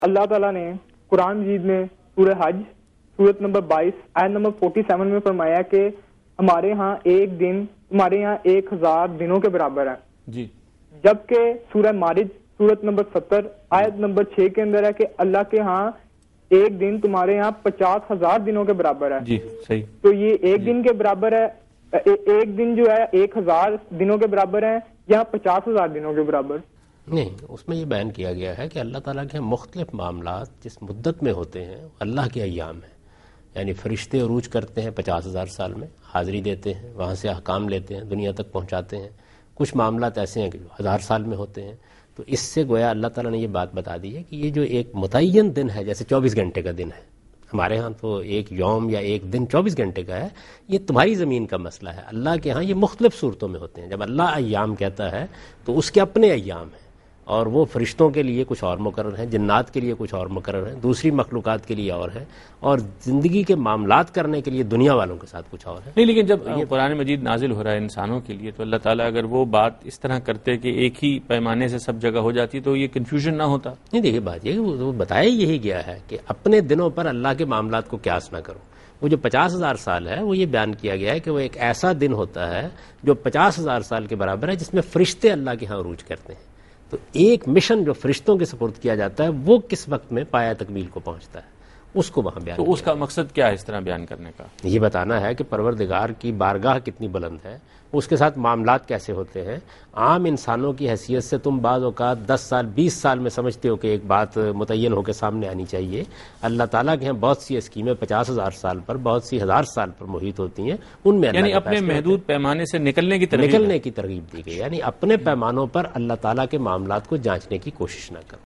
Answer to a Question by Javed Ahmad Ghamidi during a talk show "Deen o Danish" on Duny News TV
دنیا نیوز کے پروگرام دین و دانش میں جاوید احمد غامدی ”اللہ کے ہاں دن کی لمبائی“ سے متعلق ایک سوال کا جواب دے رہے ہیں